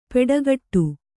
♪ peḍagaṭṭu